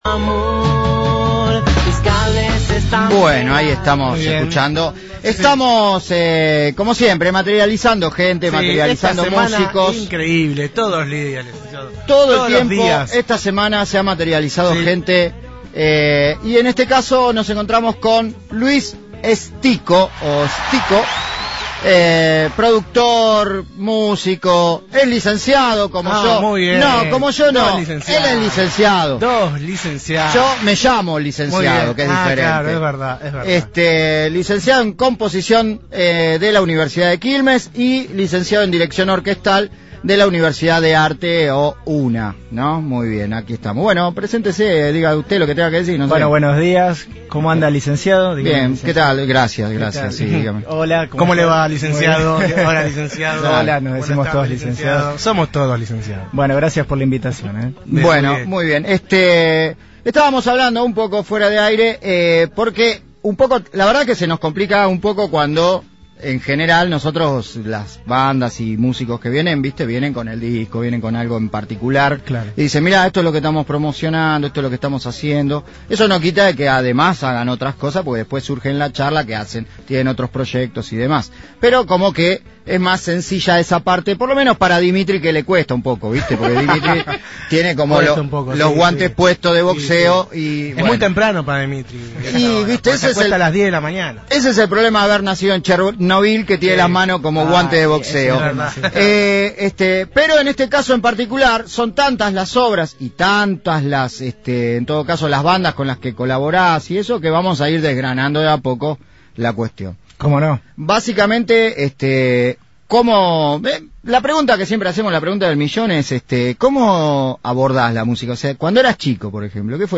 El polifacético músico estuvo en los estudios de Frecuencia Zero y compartió su obra de más de 14 años en la música, el teatro y la danza.
Entrevistas